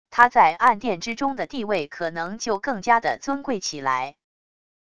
他在暗殿之中的地位可能就更加的尊贵起来wav音频生成系统WAV Audio Player